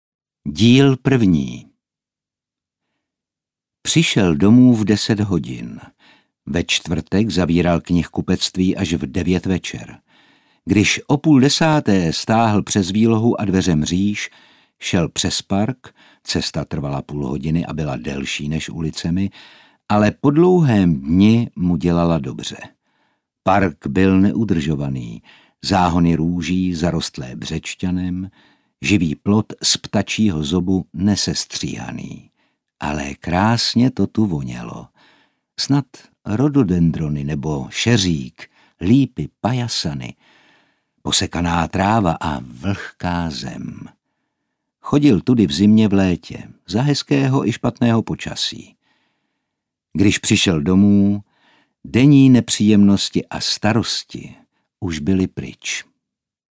Nabízím profesionální úpravy vámi nahraného mluveného slova - voiceover, podcast, audiokniha aj.
* střih audia pro plynulost toku řeči
* úprava barvy hlasu s pomocí EQ
* úprava dynamiky s pomocí komprese